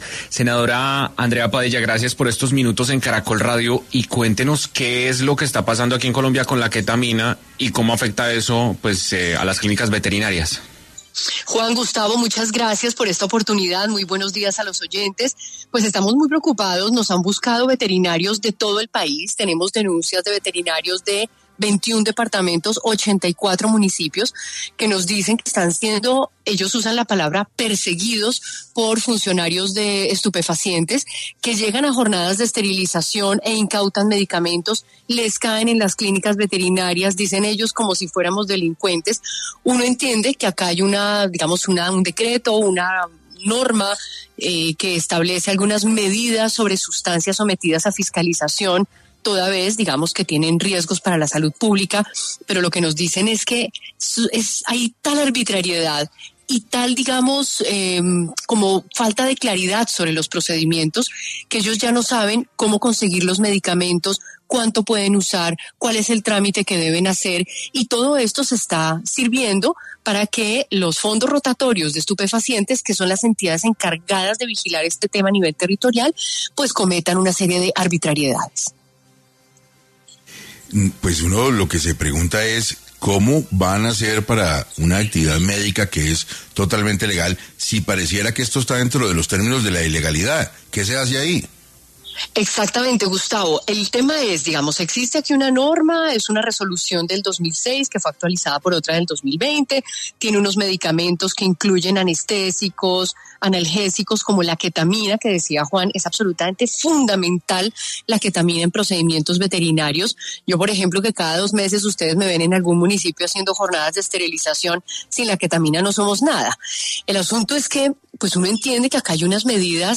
Andrea Padilla, senadora de la República, habló hoy para 6AM sobre el consumo ilícito de ketamina en el país y cómo está afectando al gremio de veterinarios.